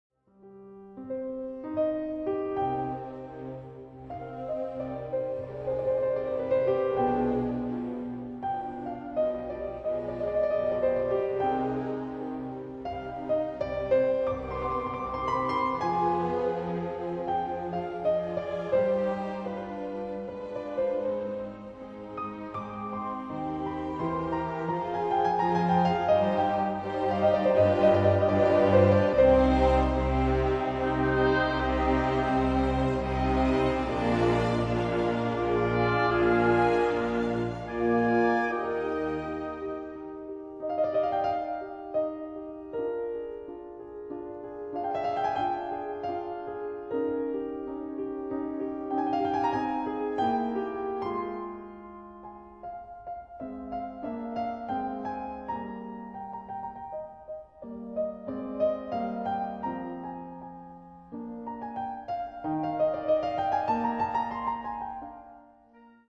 No 9, 2nd Movt (piano entry) ,